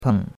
peng3.mp3